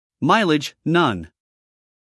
英音/ ˈmaɪlɪdʒ / 美音/ ˈmaɪlɪdʒ /